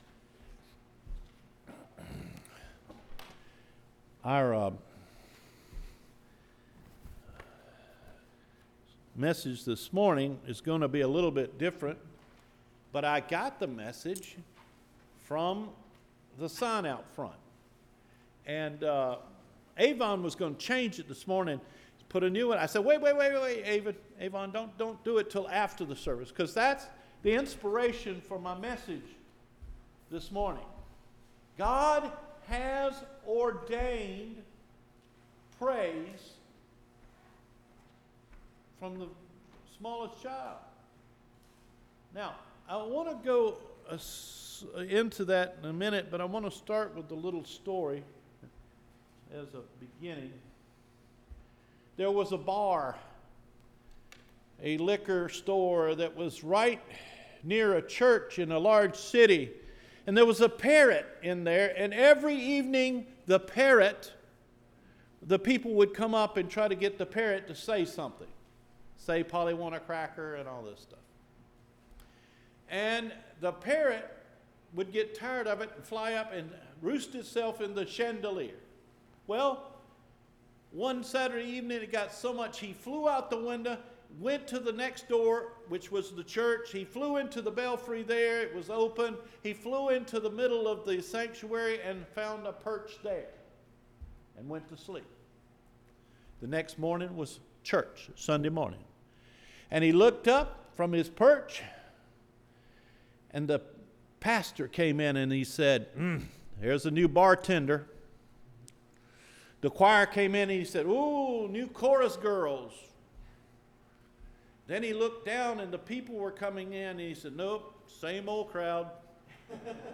Recorded Sermons